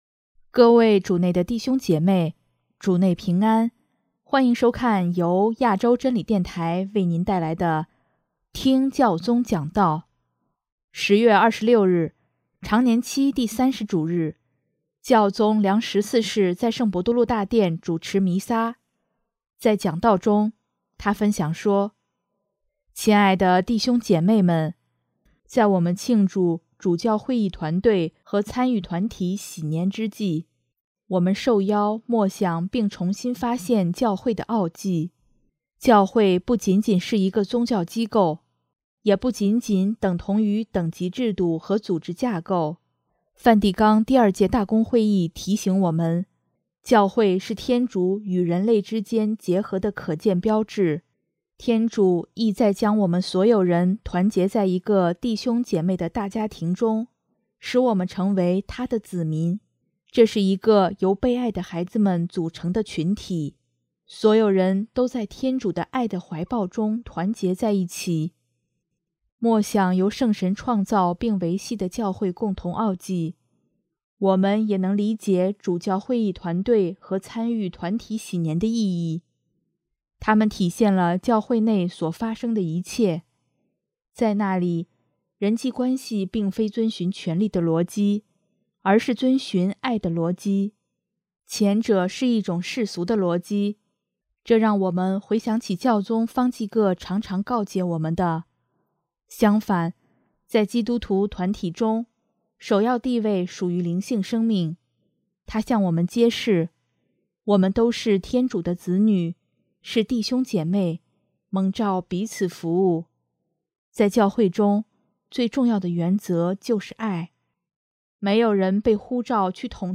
10月26日，常年期第三十主日，教宗良十四世在圣伯多禄大殿主持弥撒，在讲道中，他分享说：